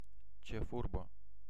Ääntäminen
IPA : /ˈkæp.ɪ.təl/